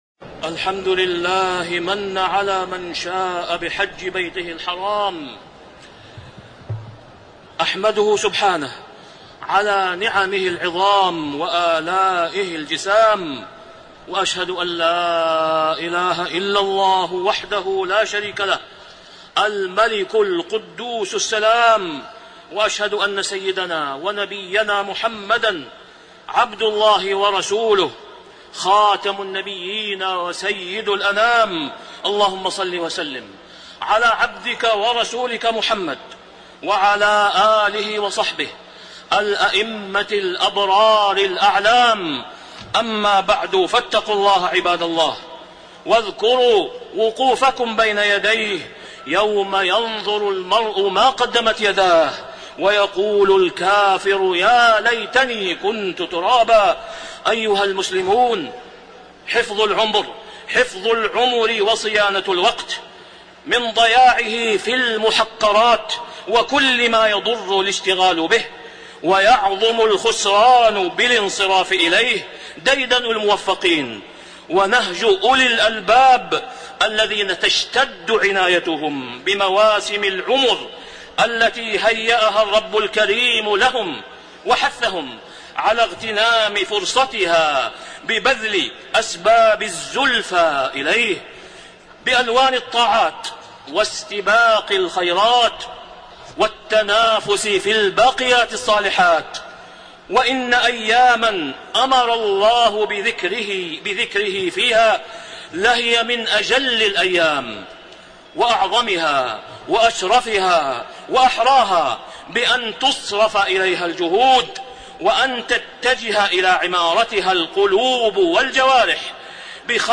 تاريخ النشر ١٠ ذو الحجة ١٤٣٣ هـ المكان: المسجد الحرام الشيخ: فضيلة الشيخ د. أسامة بن عبدالله خياط فضيلة الشيخ د. أسامة بن عبدالله خياط فضل الذكر في أيام التشريق